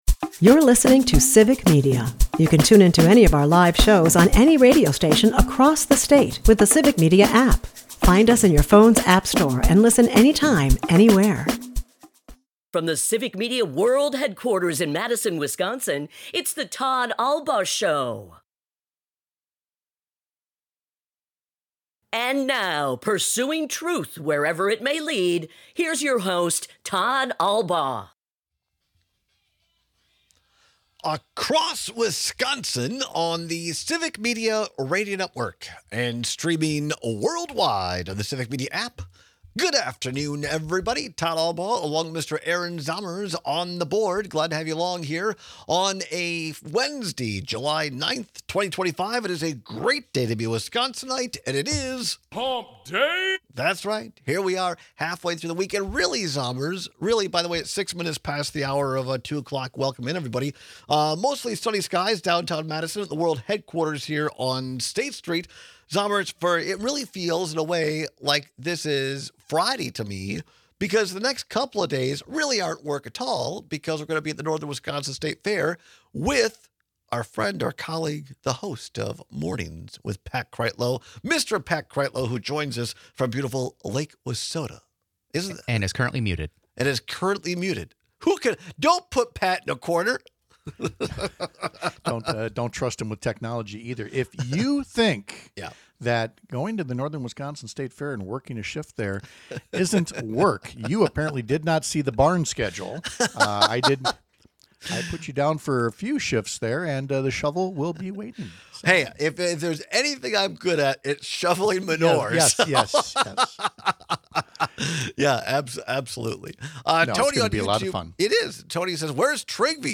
airs live Monday through Friday from 2-4 pm across Wisconsin